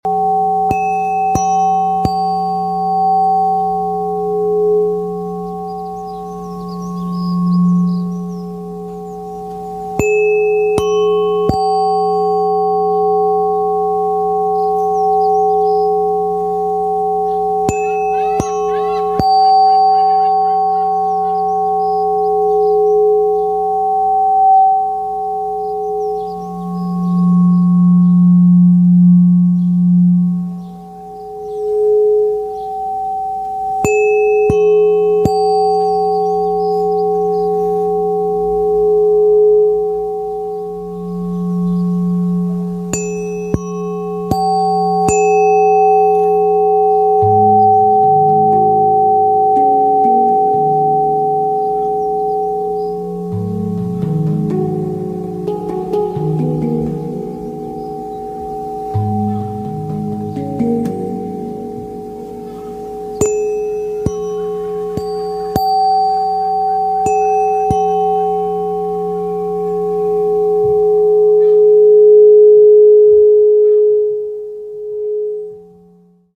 Solfeggio Frequencies Tuning Forks | sound effects free download
Root Chakra 174hz, Sacral Chakra 417hz, Throat Chakra 741hz